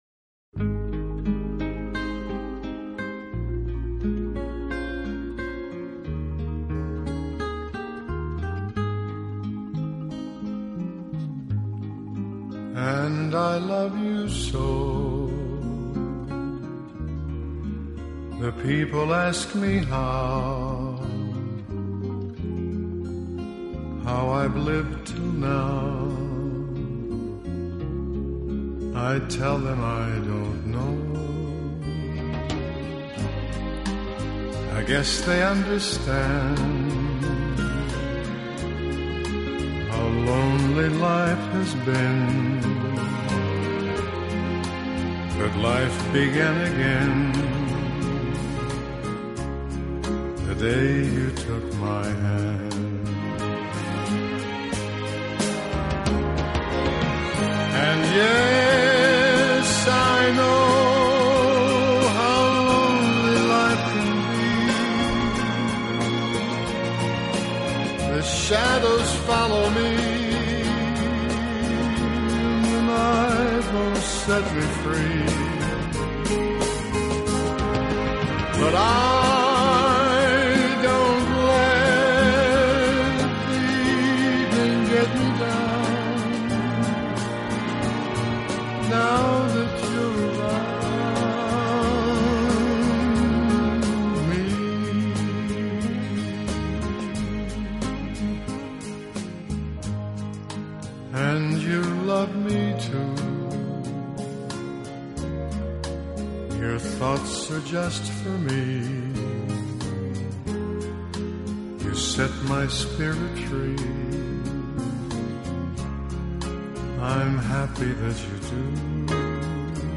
浪漫、最优美的纯音乐，超时空经典，超想像完美。
浪漫的国度、浪漫的音乐、浪漫的演绎、浪漫的情怀……